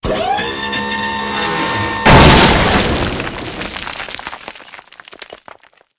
crushbrick.ogg